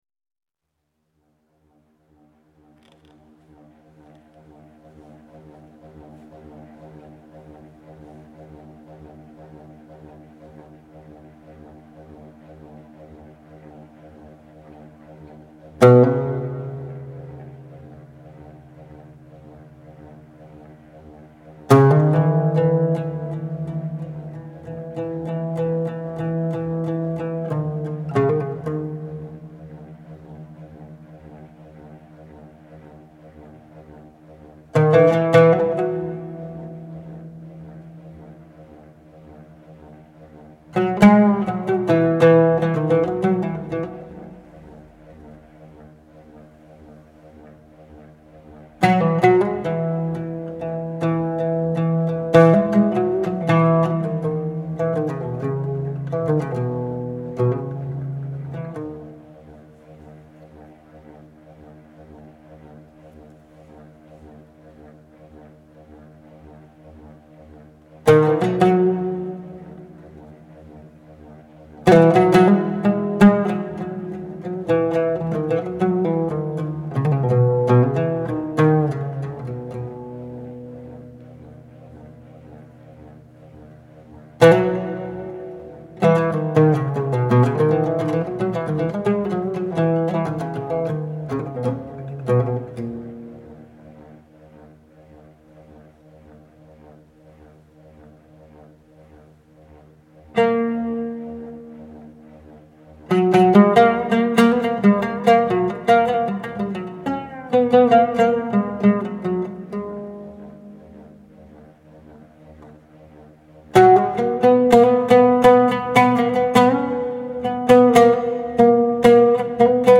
oud taksim (melodic exploration)